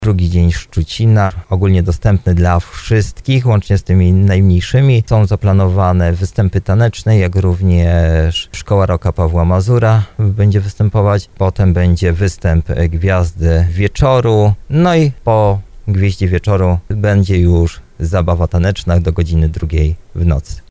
Jak mówi zastępca burmistrza Szczucina Tomasz Bełzowski, będzie to okazja, aby spędzić czas z bliskimi.